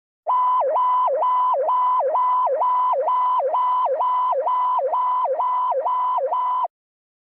BSG FX - Sighting monitor in space fighter (also used in "Buck Rogers in the 25th Century")
BSG_FX_-_Sighting_Monitor_in_Space_Fighter.wav